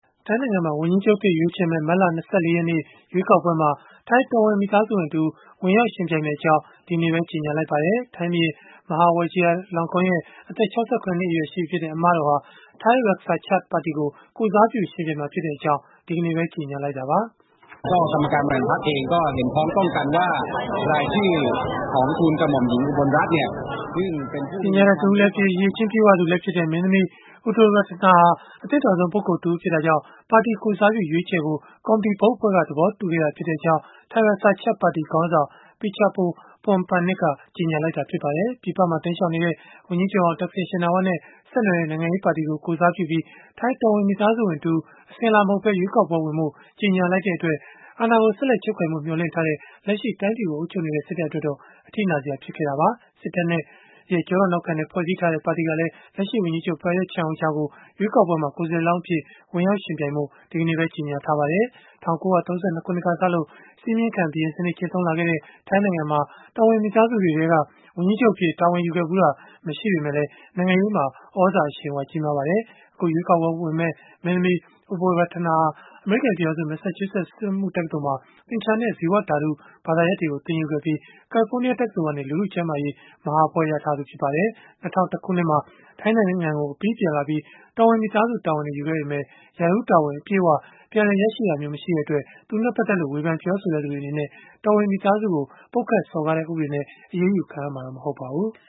ထိုင်းဘုရင့်အမတော် ဝန်ကြီးချုပ်လောင်းအဖြစ် ထိုင်းရွေးကောက်ပွဲဝင်မည် (News)